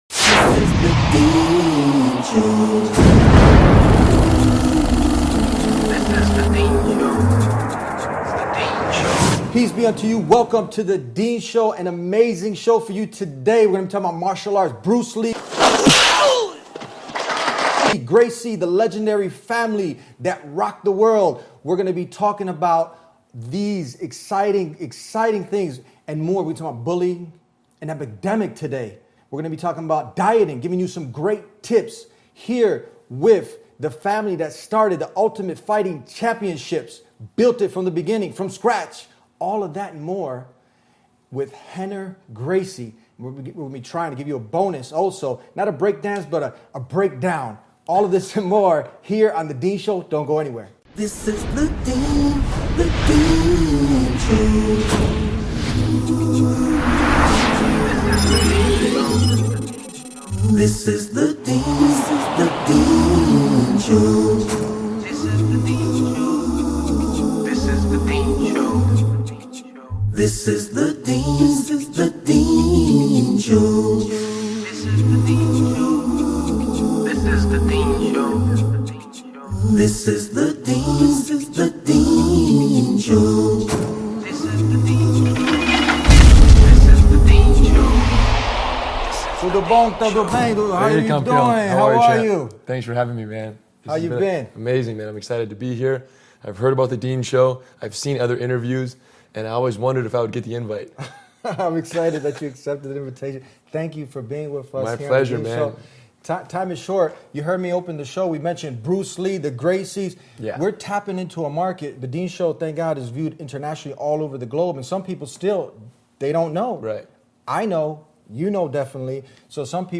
Rener Gracie, a member of the legendary Gracie family that founded Brazilian Jiu-Jitsu and built the Ultimate Fighting Championships from scratch, joined The Deen Show for an exciting conversation about martial arts, self-defense, bullying prevention, and the timeless question: who would win in a fight between Bruce Lee and the Gracies? This episode bridges the worlds of martial arts and Islamic values of discipline, self-improvement, and protecting the vulnerable.